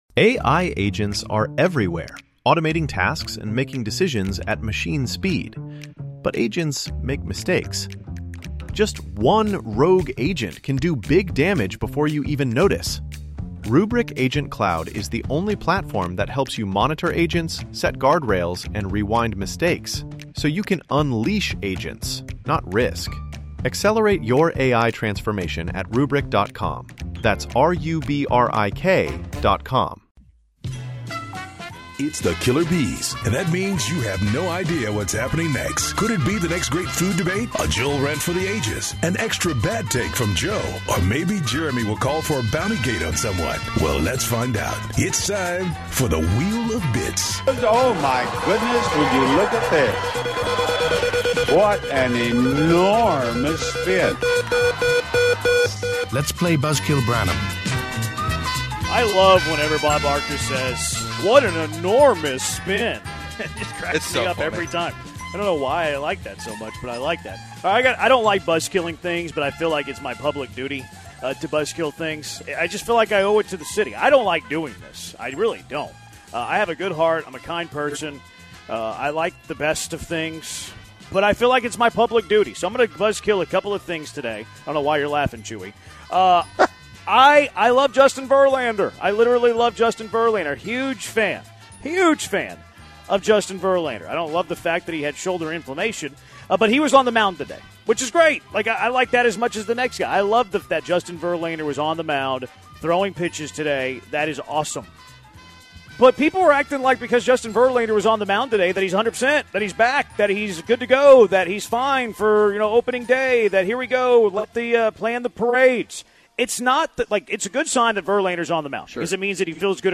In the second hour of The Killer B's live from The Decoy: